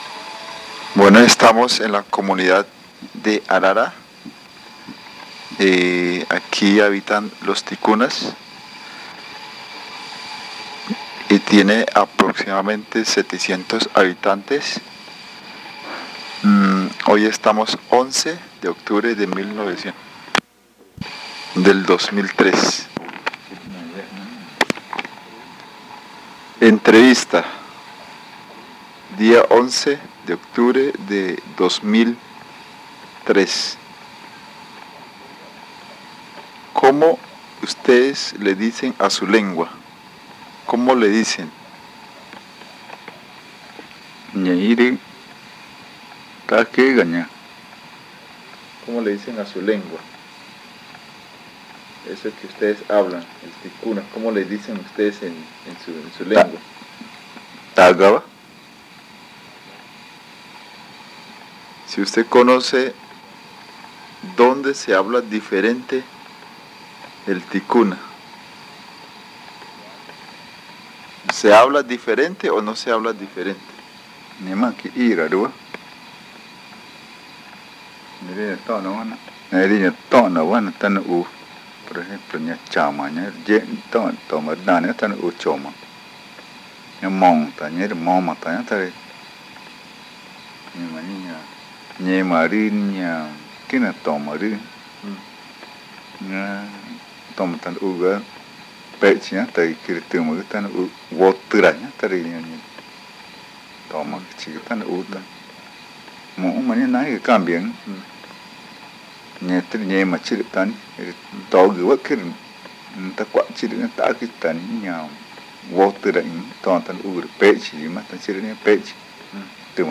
Este casete es el primero de una serie de tres casetes que se grabaron en torno a la variedad magütá hablada en Arara. El audio contiene los lados A y B.